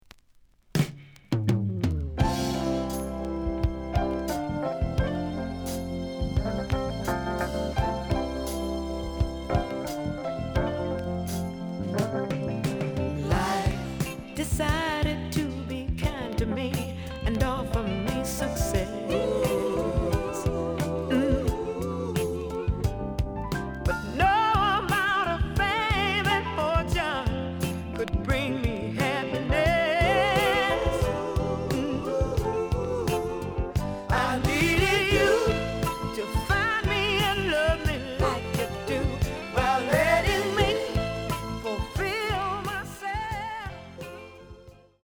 The listen sample is recorded from the actual item.
●Genre: Soul, 70's Soul
B side plays good.)